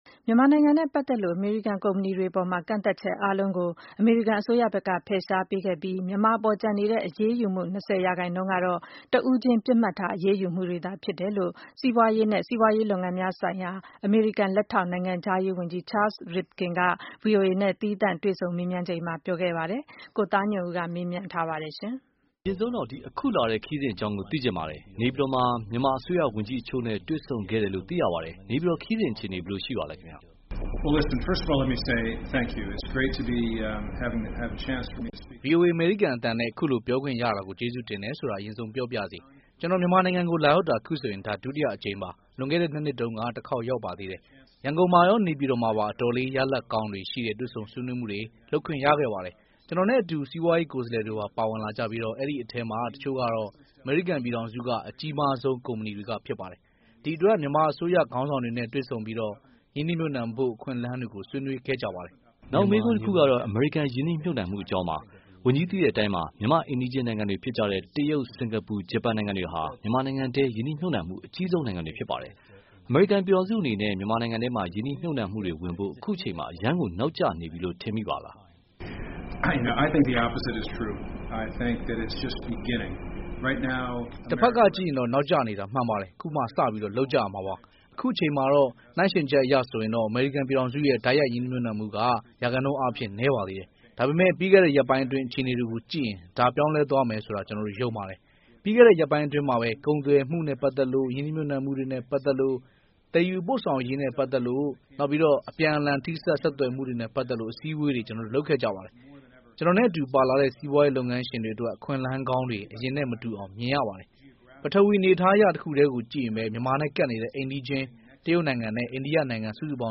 အမေရိကန်လက်ထောက်နိုင်ငံခြားရေးဝန်ကြီး Charles Rivkin နဲ့ voa မြန်မာပိုင်း သီးခြားတွေ့ဆုံမေးမြန်းမှု